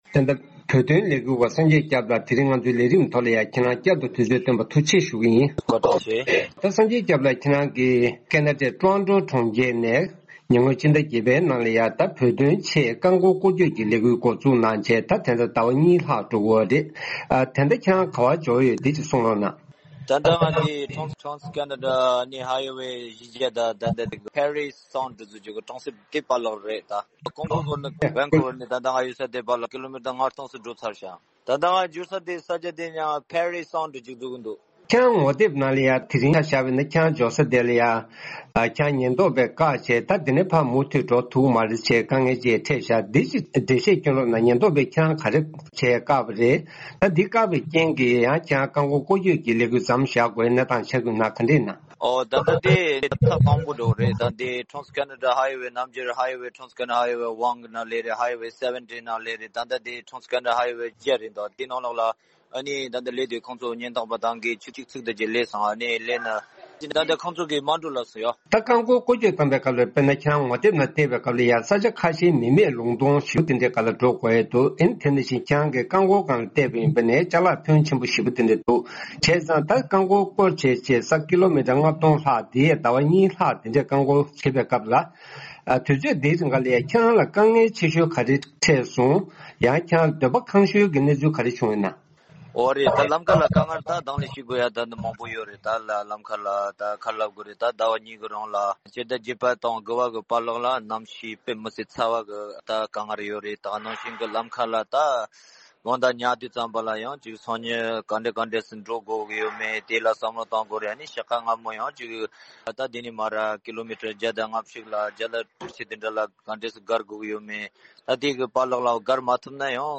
ཁོང་ལ་བཅར་འདྲི་ཞུས་པར་གསན་རོགས་ཞུ